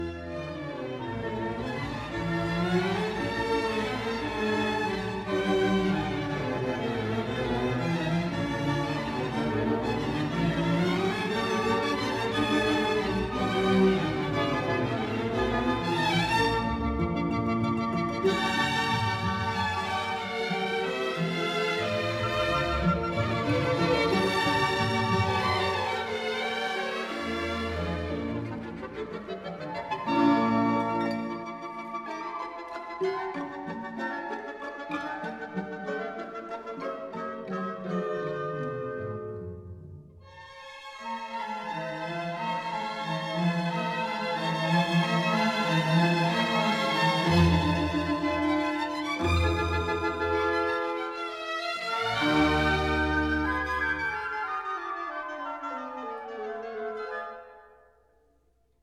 conductor
1958 stereo recording